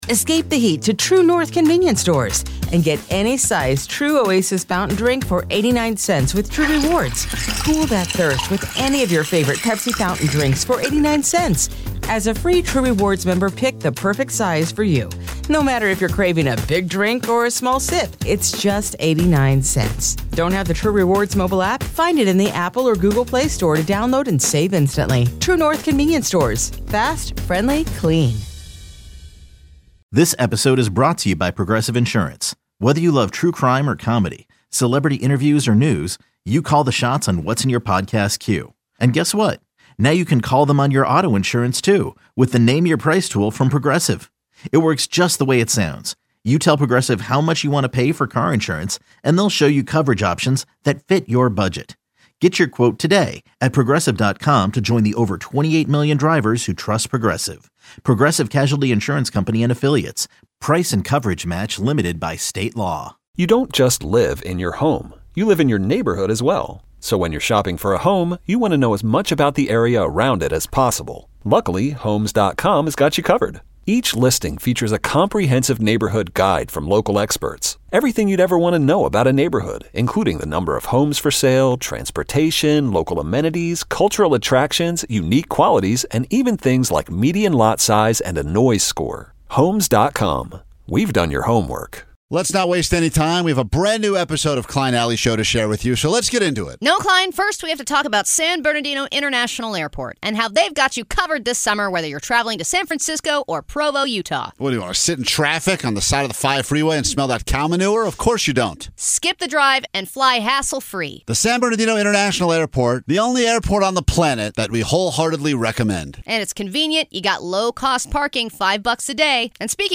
the show is known for its raw, offbeat style, offering a mix of sarcastic banter, candid interviews, and an unfiltered take on everything from culture to the chaos of everyday life. With a loyal, engaged fanbase and an addiction for pushing boundaries, the show delivers the perfect blend of humor and insight, all while keeping things fun, fresh, and sometimes a little bit illegal.